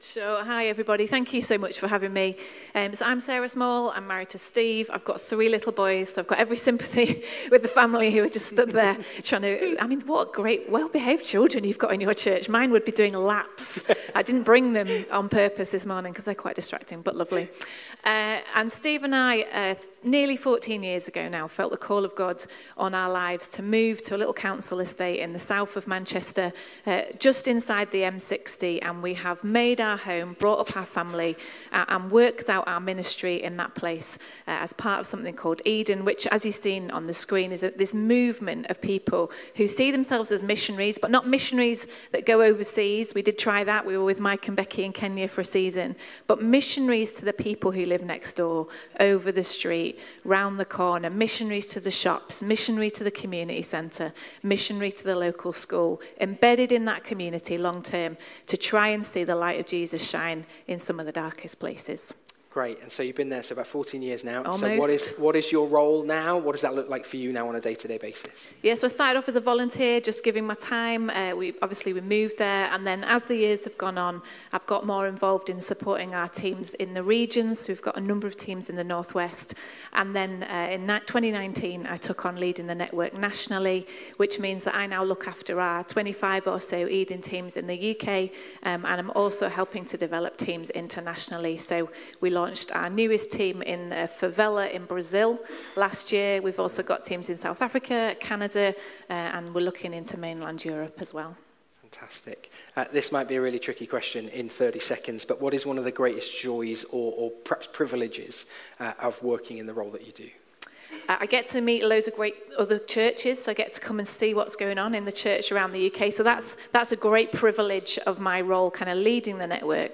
Passage: John 1:1-14 Service Type: Sunday Morning